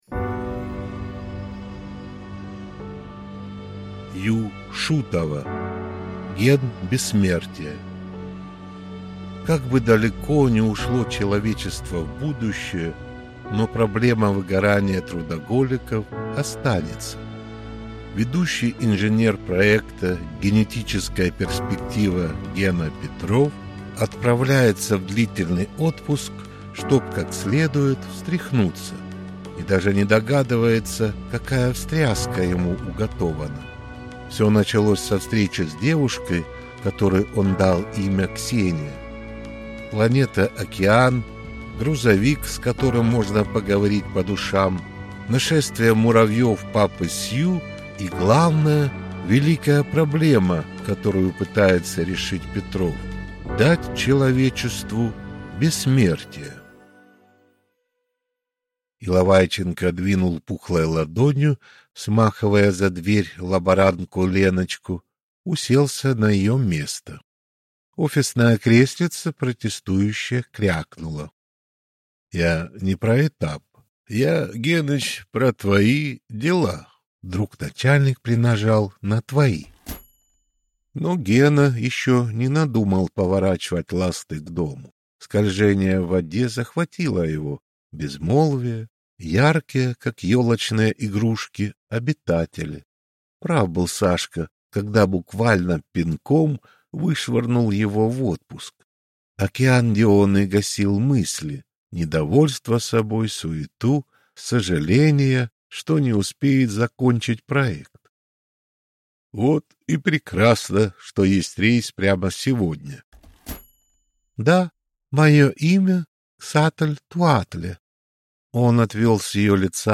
Аудиокнига Ген бессмертия | Библиотека аудиокниг